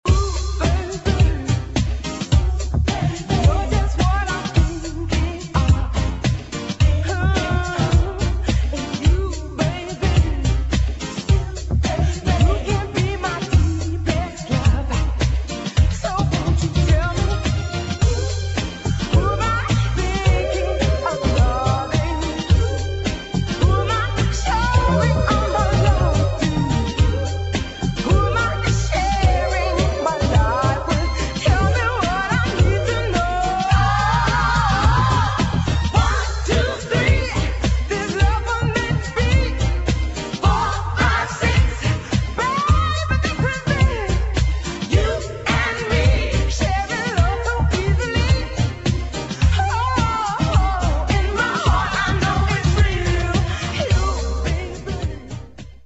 1. HOUSE | DISCO